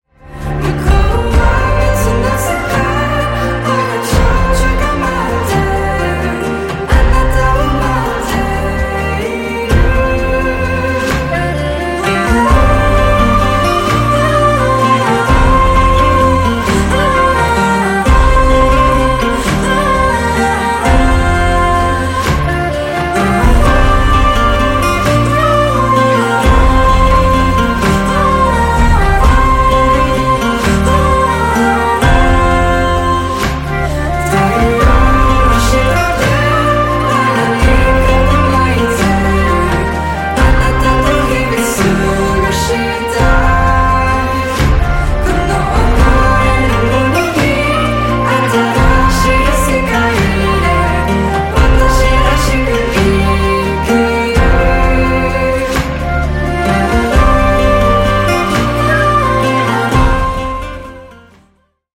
Après le studio ! ✨